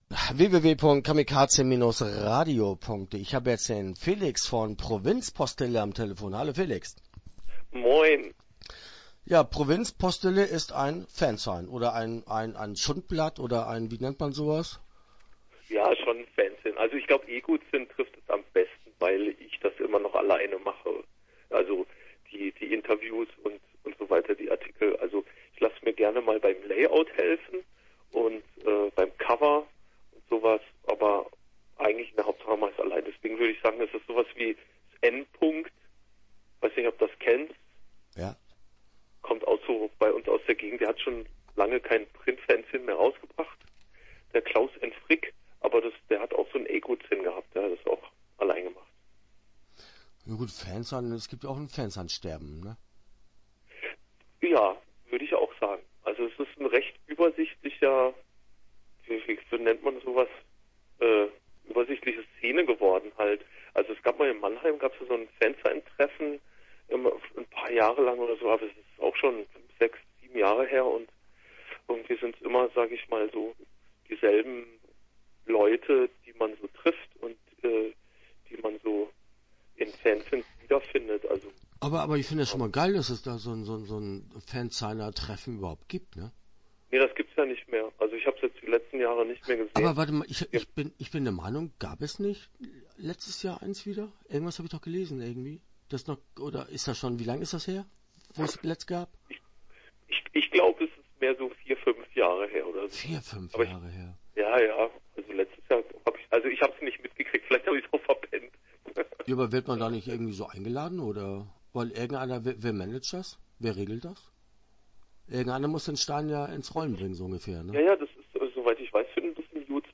Start » Interviews » Provinz Postille